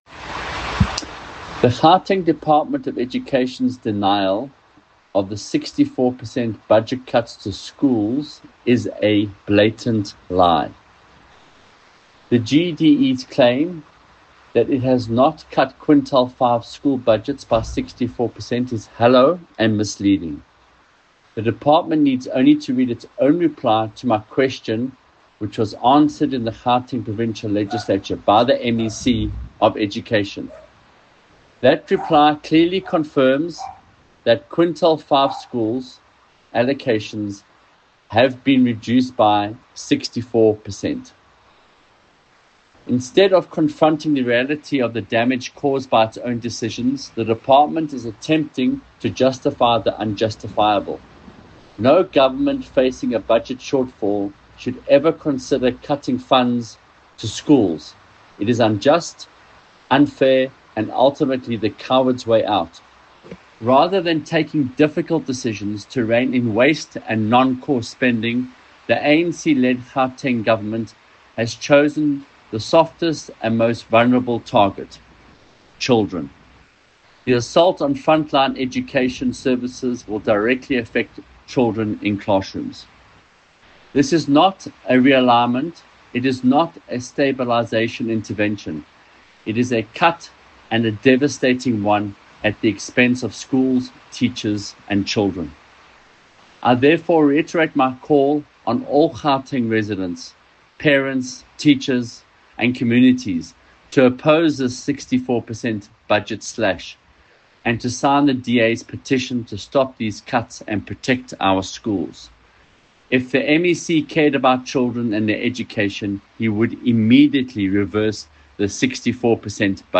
soundbite by Michael Waters MPL.